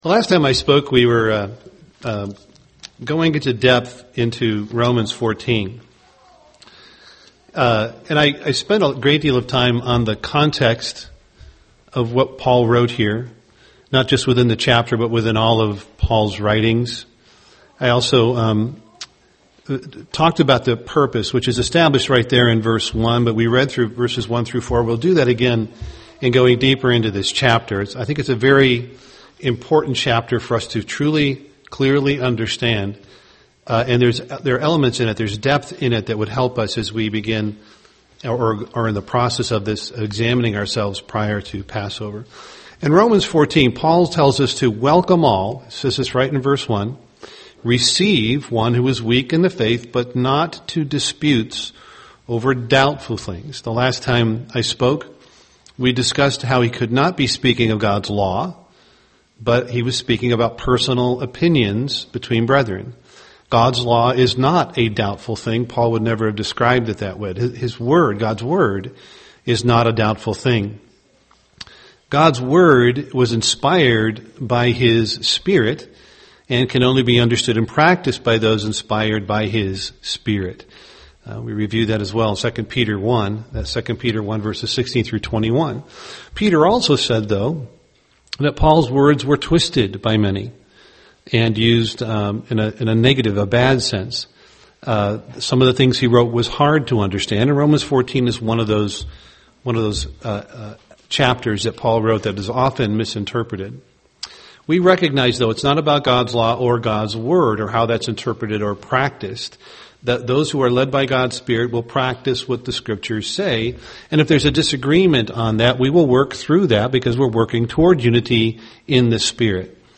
UCG Sermon Romans 14 stumbling block Studying the bible?